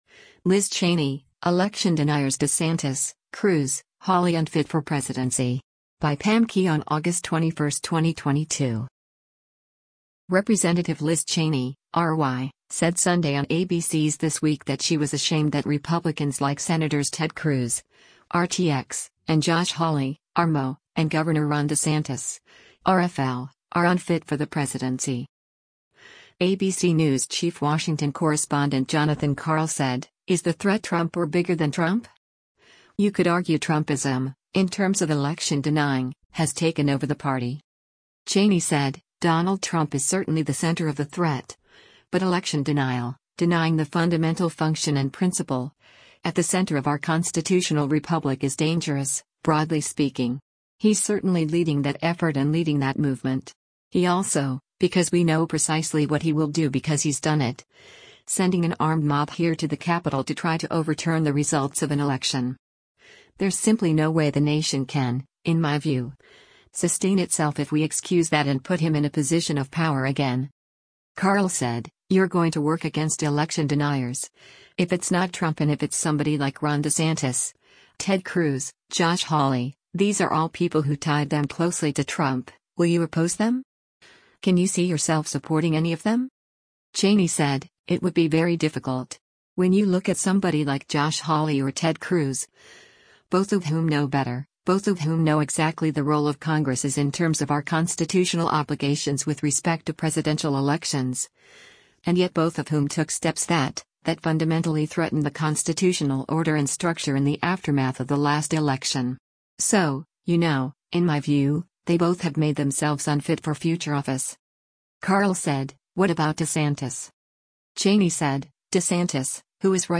Representative Liz Cheney (R-WY) said Sunday on ABC’s “This Week” that she was “ashamed” that Republicans like Sens. Ted Cruz (R-TX) and Josh Hawley (R-MO) and Gov. Ron Desantis (R-FL) are unfit for the presidency.